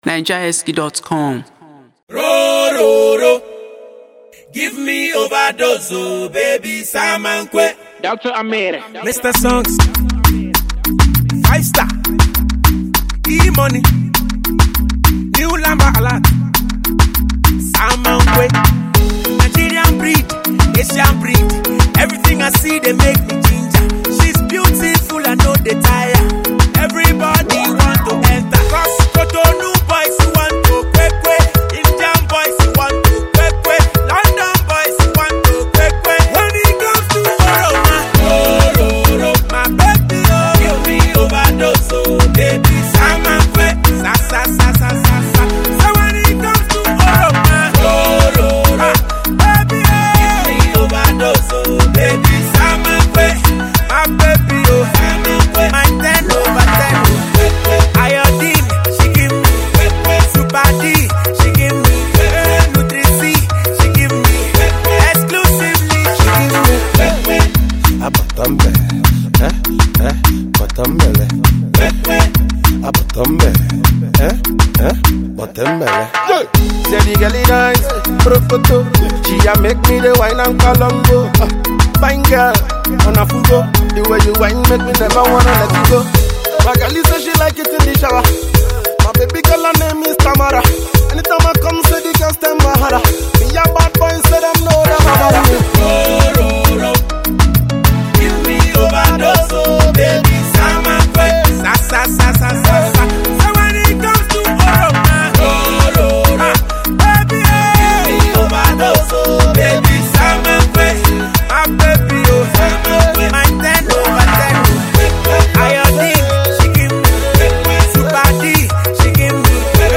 dancehall/reggae singer
groovy tune